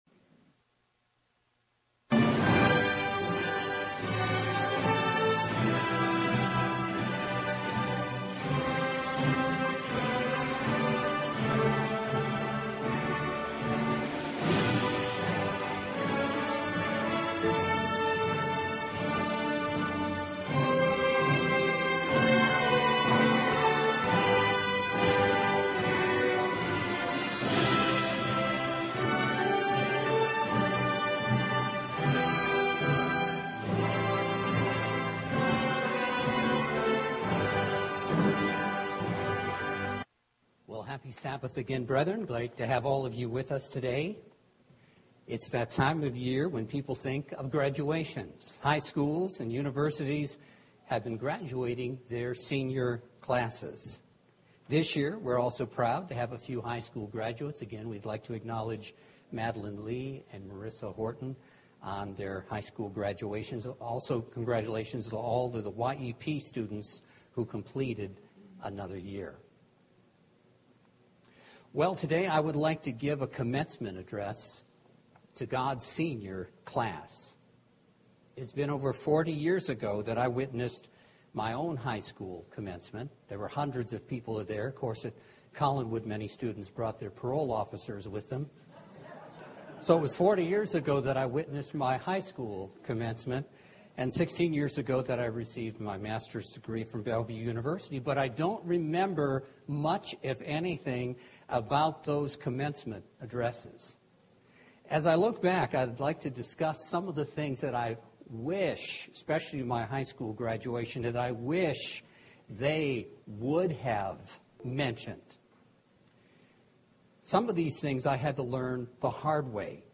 This sermon is for everyone who views each day a new beginning and a fresh start